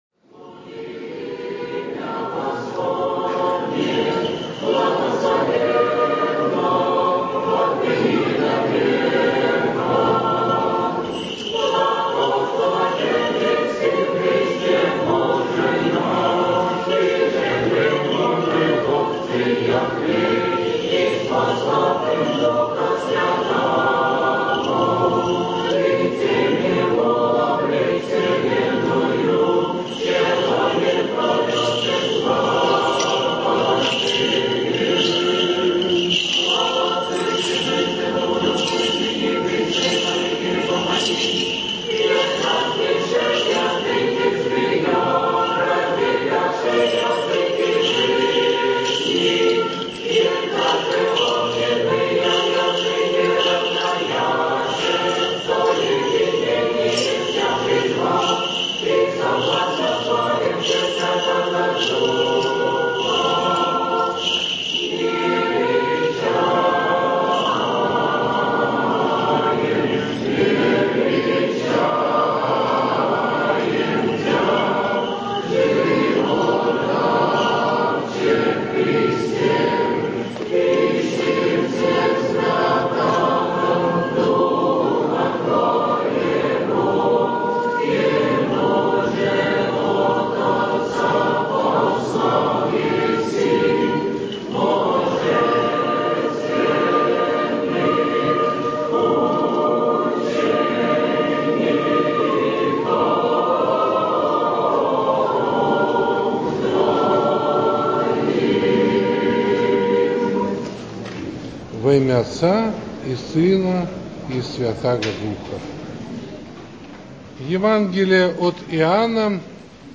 Проповедь на День Святой Троицы (протоиерей Димитрий Смирнов )